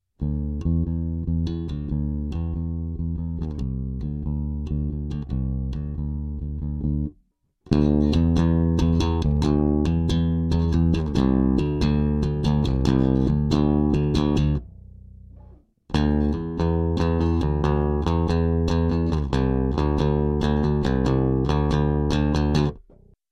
Electric Bass Finger Then Plectrum
In this sample the same 140 BPM rock riff is played:
1. With normal finger style
2. With Steve Harris-style aggressive finger technique,
3. And with a hard plastic pick (plectrum).
bt3_1_Electric_Bass_Finger_Then_Pick.mp3